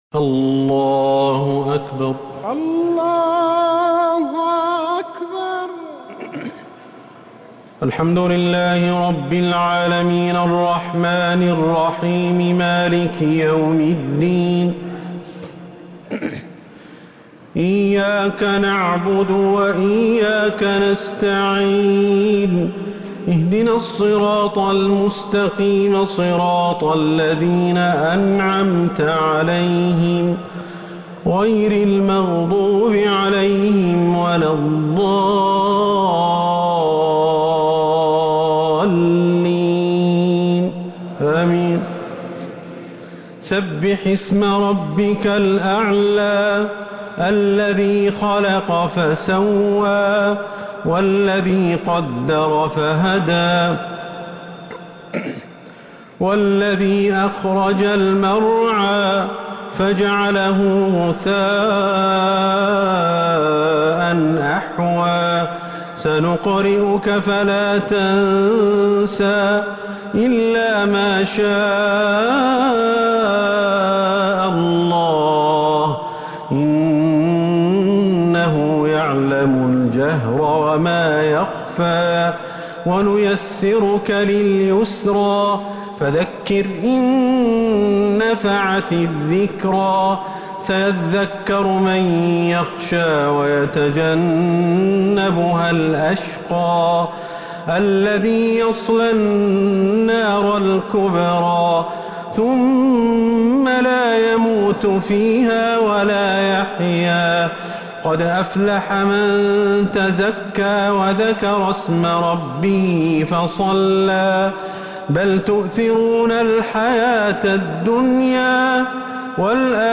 الشفع و الوتر ليلة 16 رمضان 1447هـ | Witr 16th night Ramadan 1447H > تراويح الحرم النبوي عام 1447 🕌 > التراويح - تلاوات الحرمين